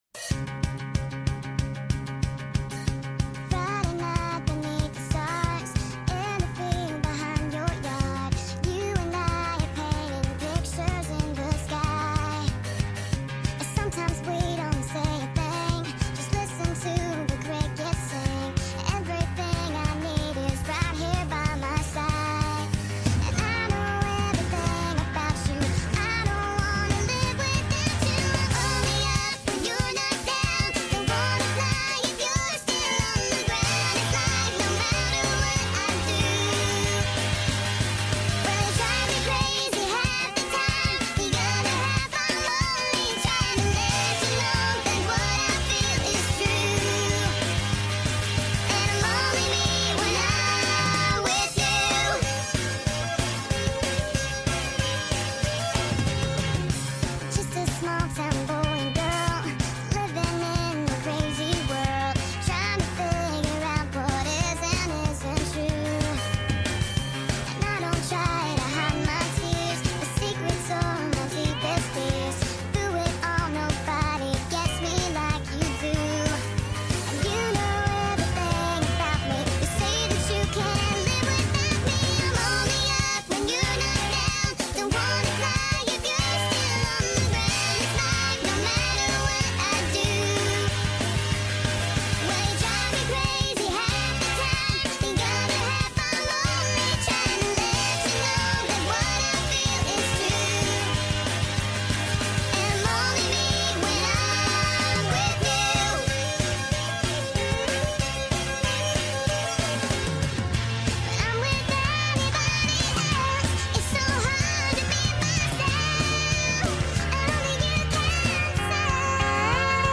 FULL SONG SPED UP AUDIO